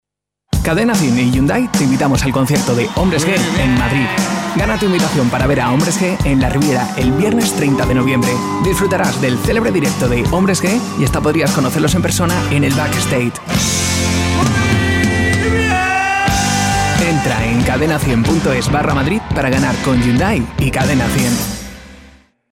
kastilisch
Sprechprobe: Sonstiges (Muttersprache):